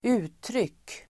Uttal: [²'u:tryk:]